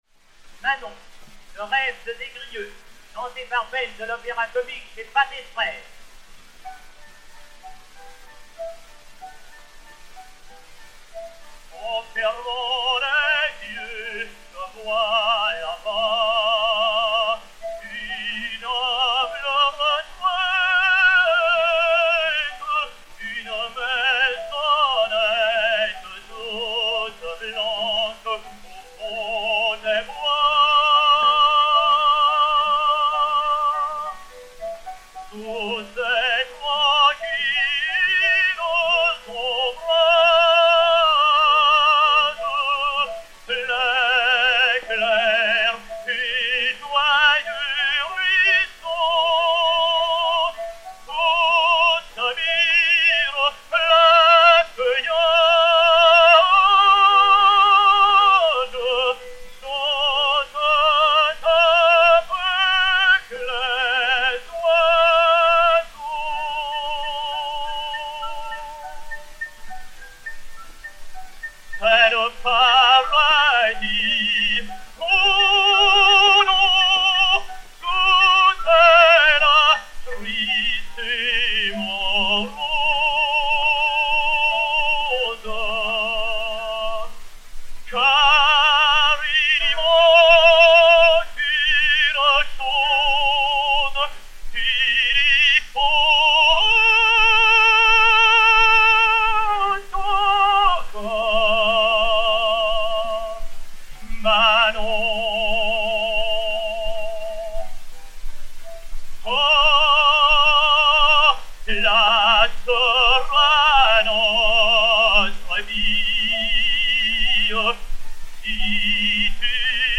Léon Beyle (Des Grieux) et Piano